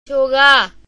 チョーガー(甘苦)